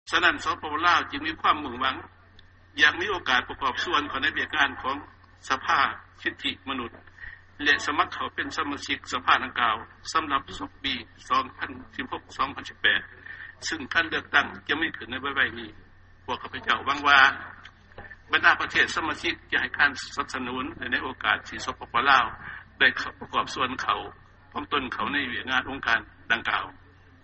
ຟັງຖະແຫລງການ ປະທານປະເທດ ຈຸມມະລີ ໄຊຍະສອນ 10